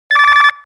phoneRing.wav